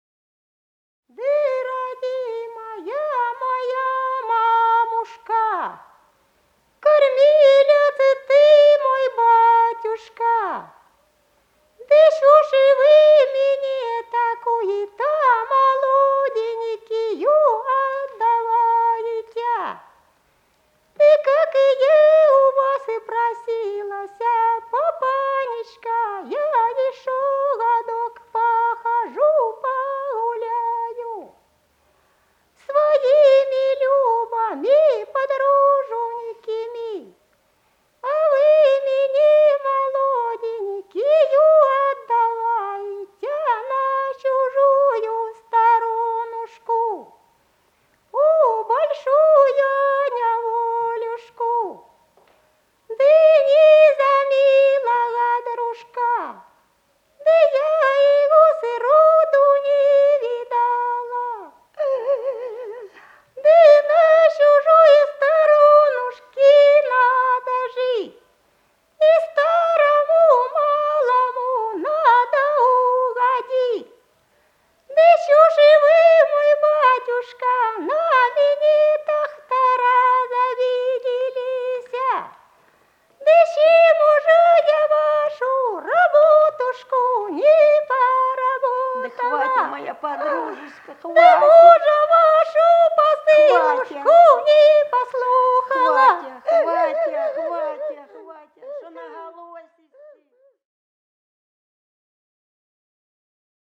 Голоса уходящего века (село Фощеватово) Да родимая ты моя мамушка (плач невесты
17._Да_родимая_ты_моя_мамушка_(плач_невесты).mp3